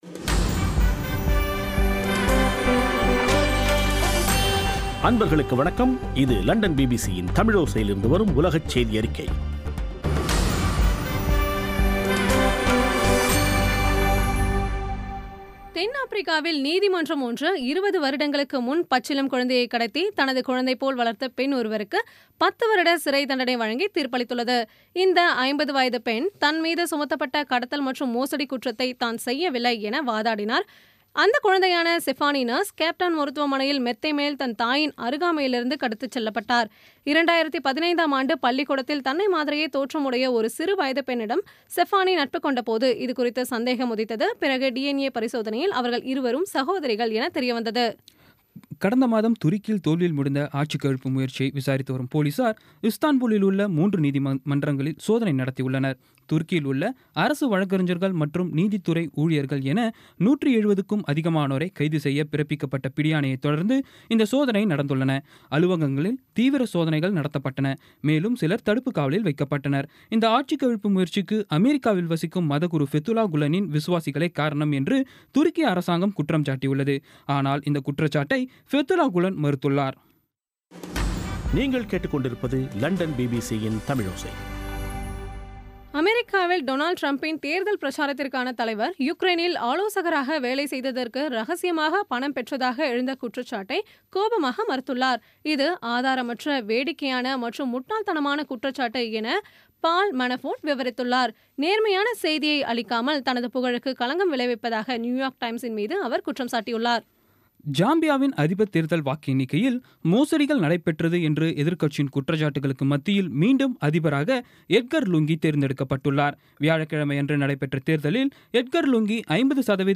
இன்றைய (ஆகஸ்ட் 15-ஆம் தேதி ) பிபிசி தமிழோசை செய்தியறிக்கை